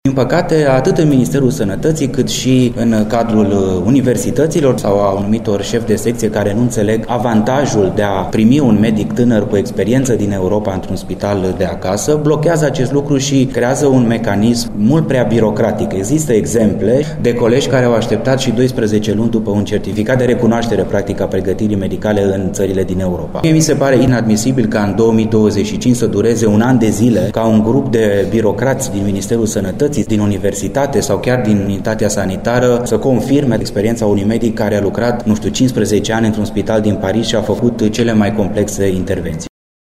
Anunțul a fost făcut la Timișoara de ministrul Sănătății, Alexandru Rogobete, care precizează că estimează nu ar trebui să dureze mai mult de 30 de zile.